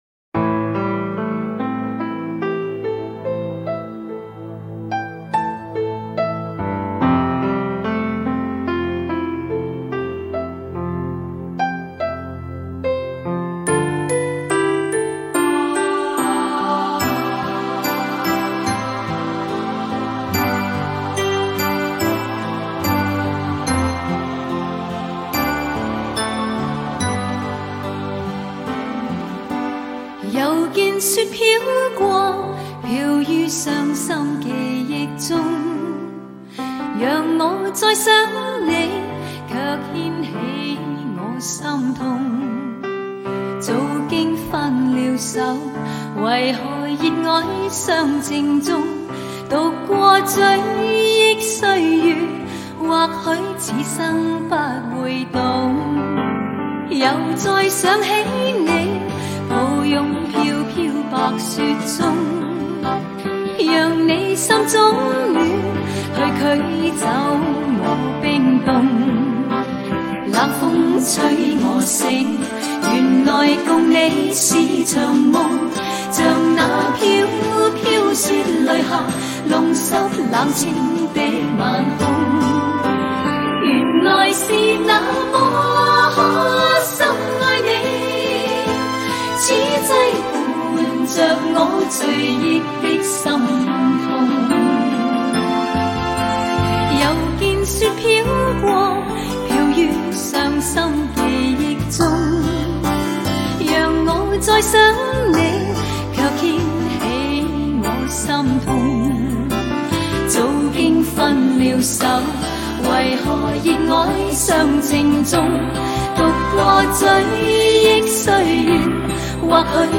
粤语 经典歌曲